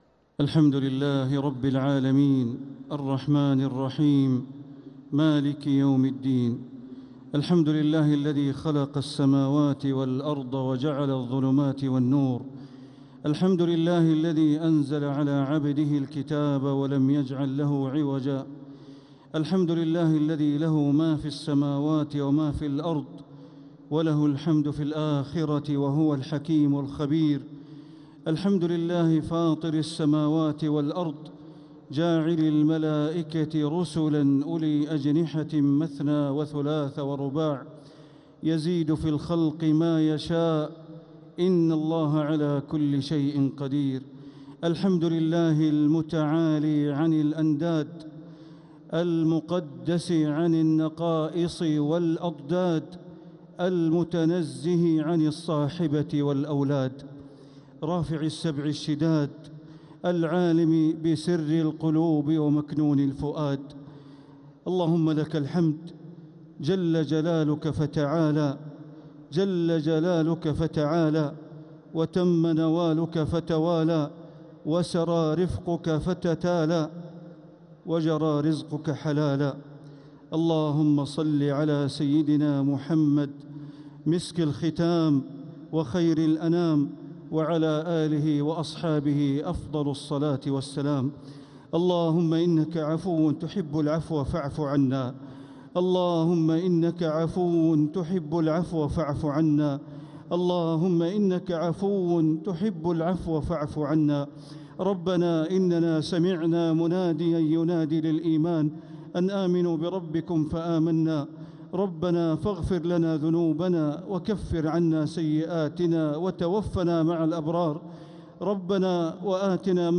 دعاء القنوت ليلة 28 رمضان 1447هـ > تراويح 1447هـ > التراويح - تلاوات بندر بليلة